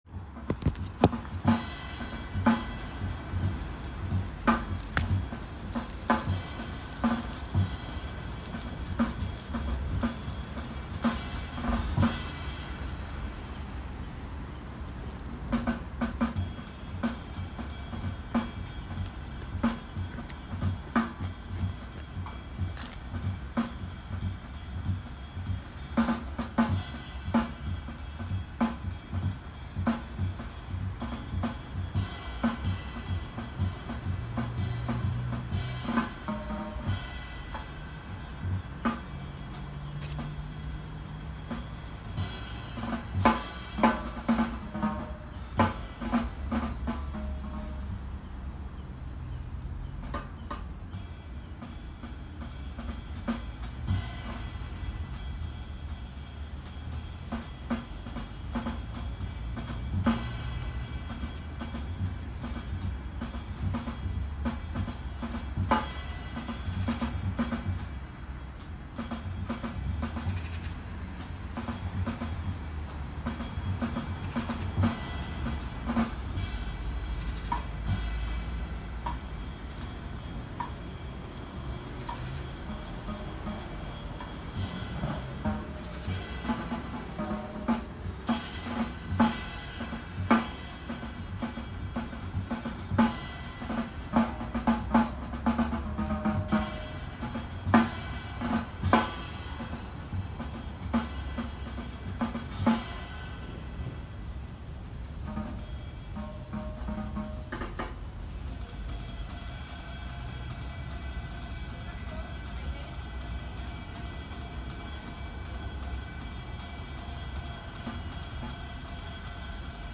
audio_surrounding_drums.mp3
CA_IDNO en -00002 Title en lunch break Description en five minutes recording birds sining and drums drumming - how exciting!!